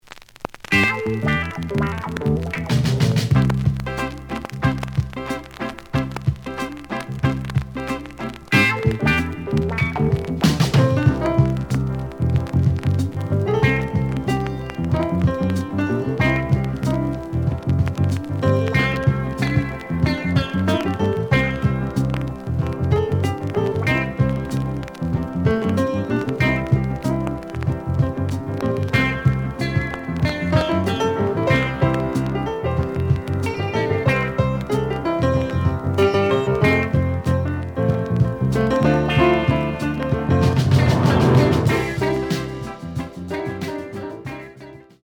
The audio sample is recorded from the actual item.